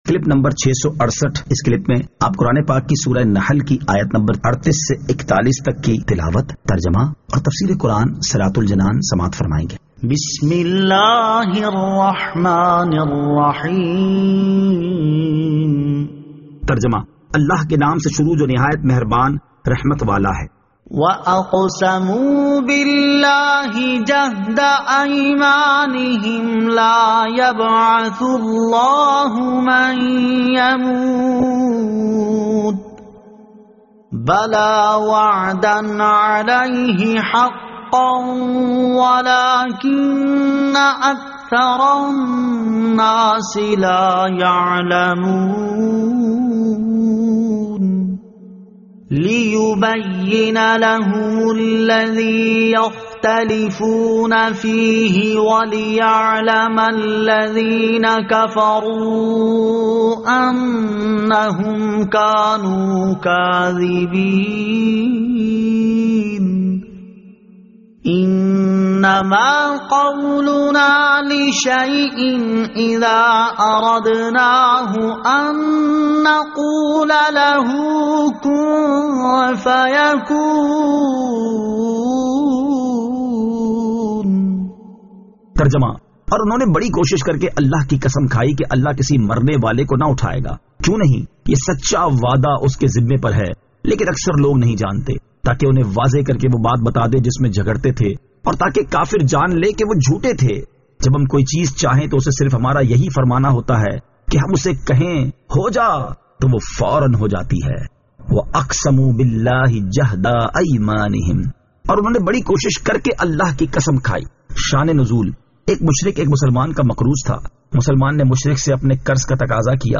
Surah An-Nahl Ayat 38 To 41 Tilawat , Tarjama , Tafseer